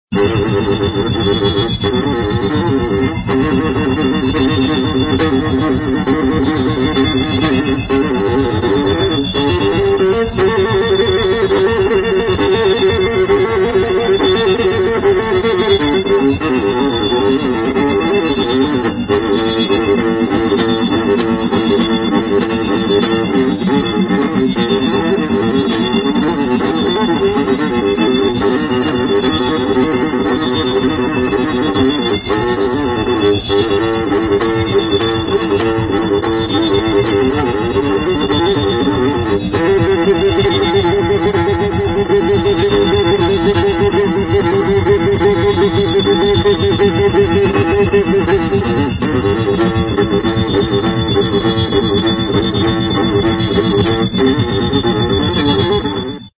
electronic, experimental